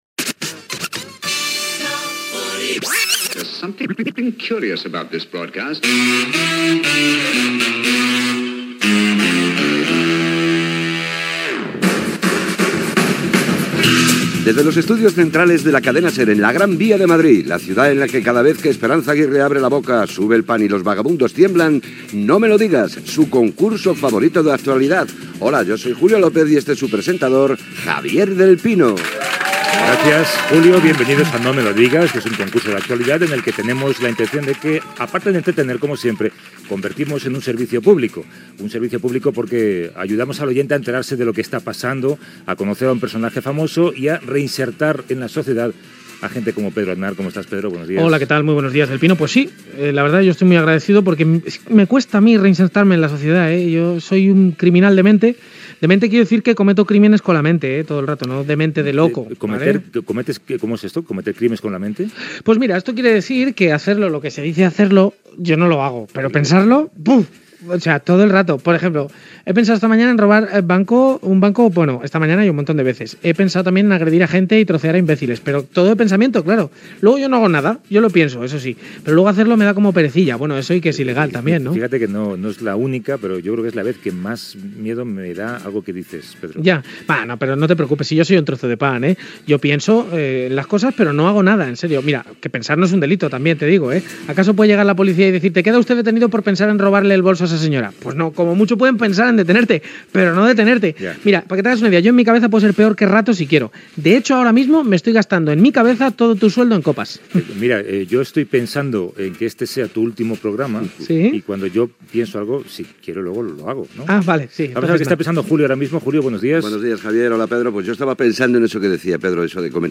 Secció "No me lo digas" amb la intervenció de l'actriu i presentadora Miriam Díaz-Aroca
Entreteniment